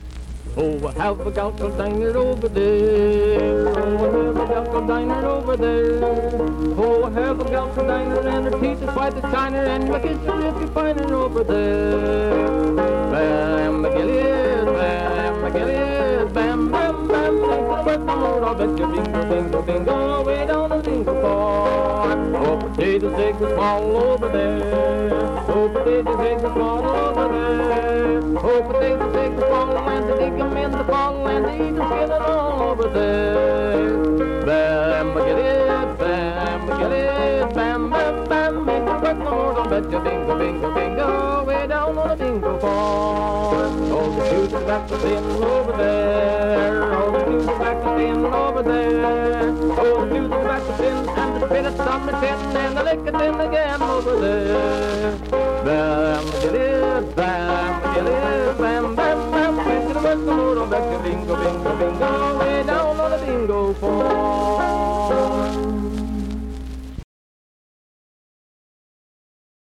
Vocal performance accompanied by banjo.
Dance, Game, and Party Songs
Banjo, Voice (sung)
Vienna (W. Va.), Wood County (W. Va.)